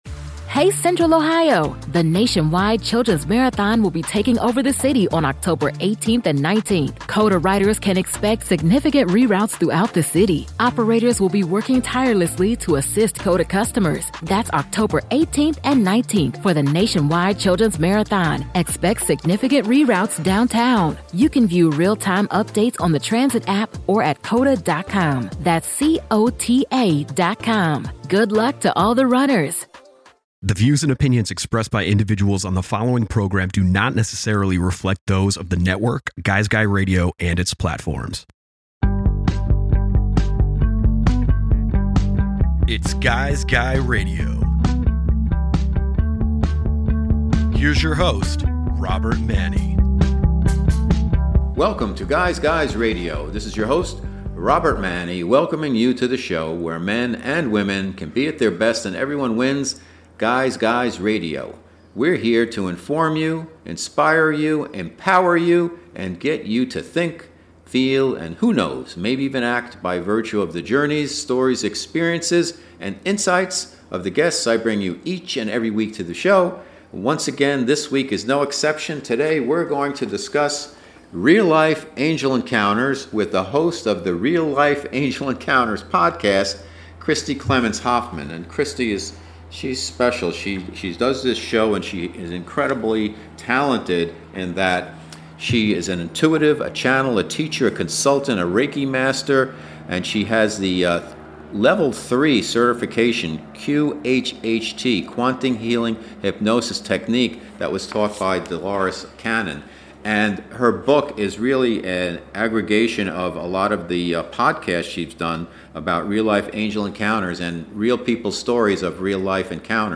GUY’S GUY RADIO features interviews and in-depth conversations with thought leaders across the worlds of relationships and modern masculinity, spirituality, health, wellness and diet, business, and much more.